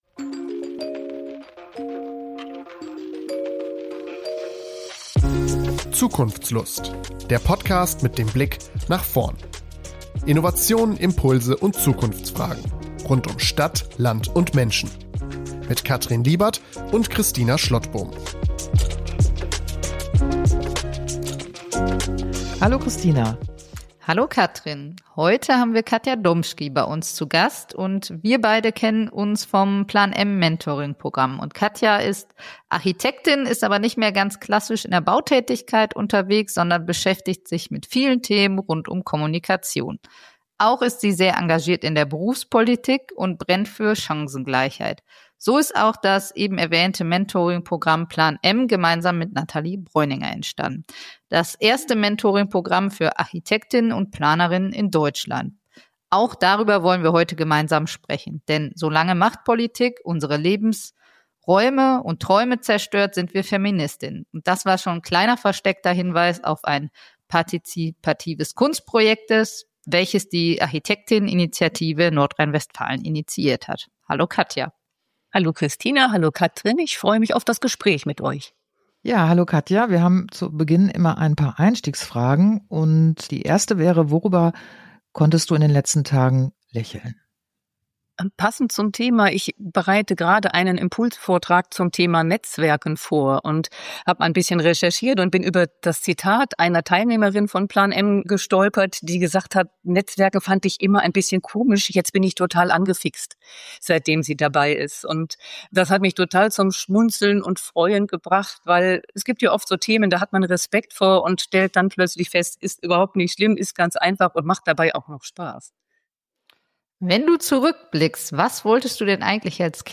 Wir sprechen über berufliche Wege, feministische Perspektiven in der Architektur, partizipative Kunstprojekte und darüber, warum es so wichtig ist, weiterhin laut für Chancengleichheit einzutreten. Ein Gespräch über Mut, Haltung und die Kraft von Netzwerken in der Planungsbranche.